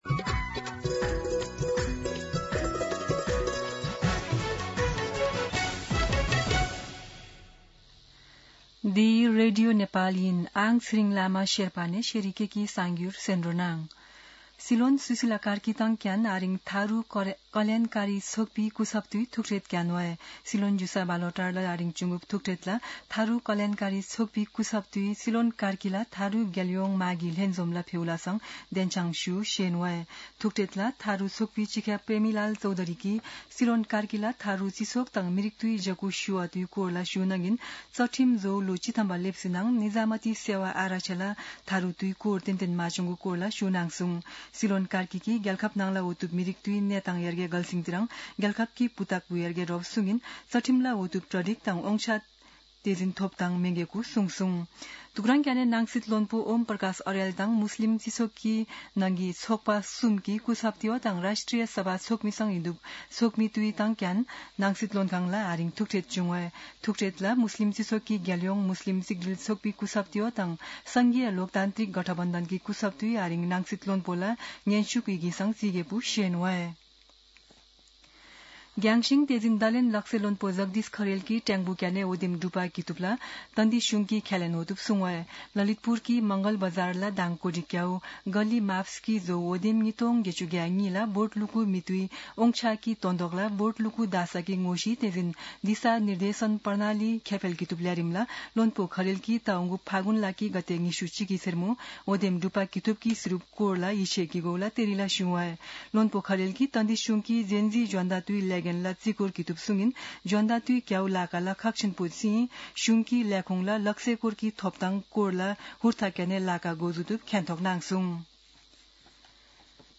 शेर्पा भाषाको समाचार : २० पुष , २०८२